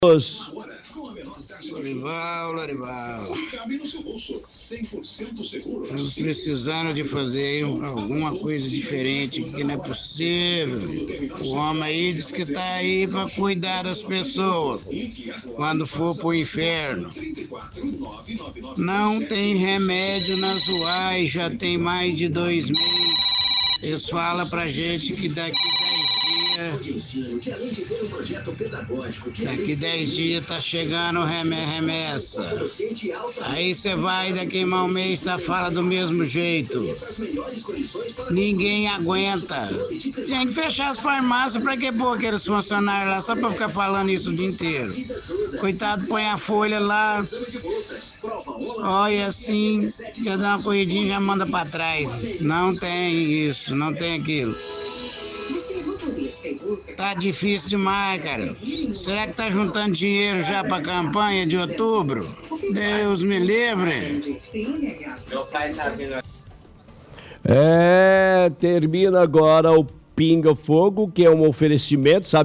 – Ouvinte fala que um pouco da culpa da saúde está ruim, é do povo, pois não reclama.